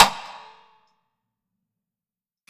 WOODEN SLAP
BA-BellySlap-Wooden-Spoon.wav